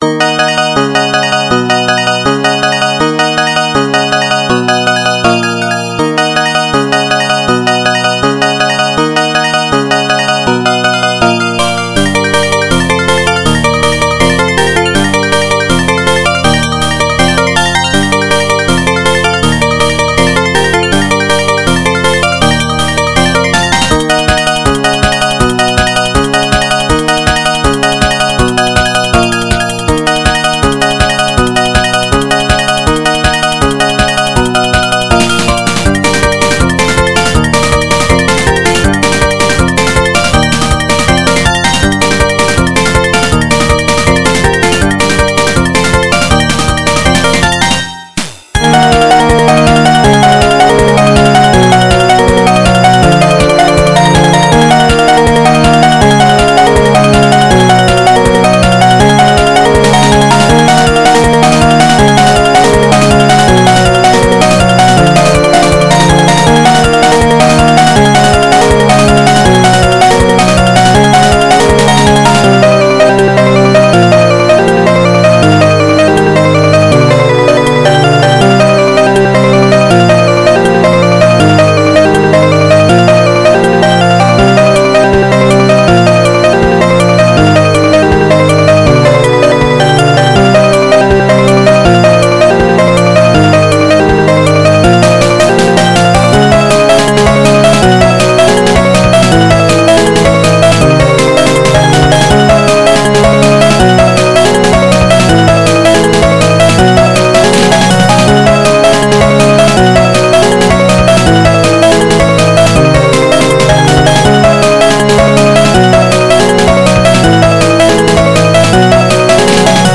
原游戏FM版，由PMDPlay导出。